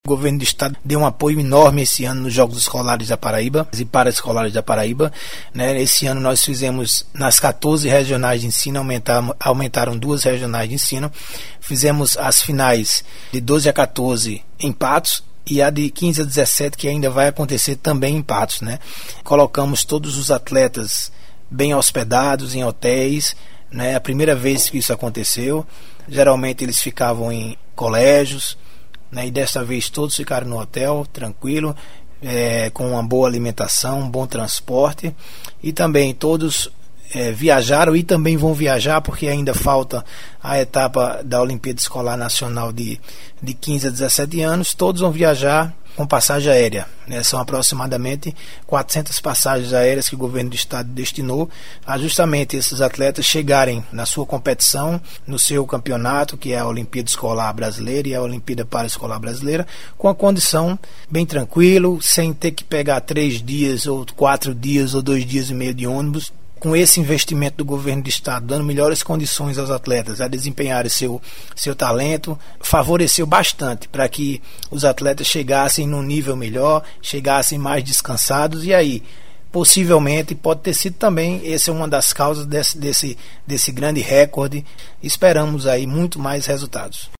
Acompanhe clicando nos links em anexo sonoras de entrevista produzida no estúdio da Secretaria de Estado da Comunicação Institucional com o secretário da Juventude, Esporte e Lazer, José Marco.